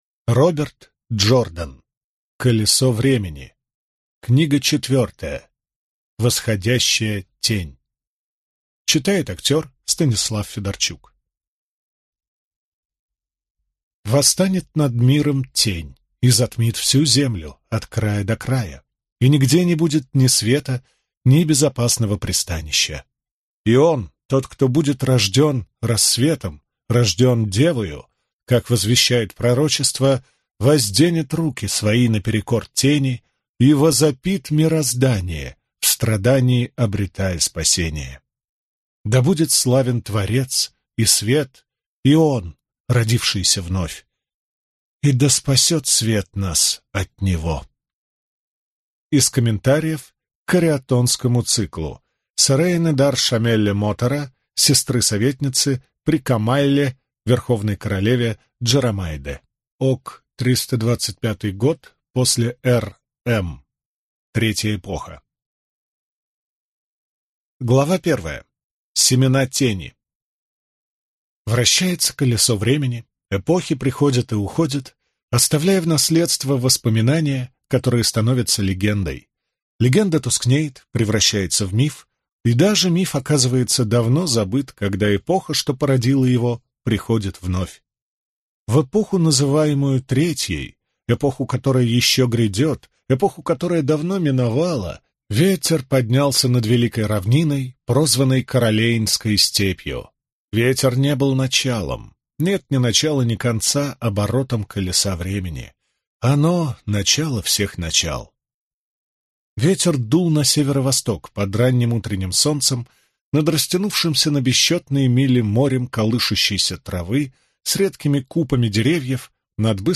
Аудиокнига Восходящая Тень | Библиотека аудиокниг